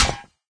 metalstone2.ogg